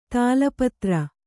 ♪ tāla patra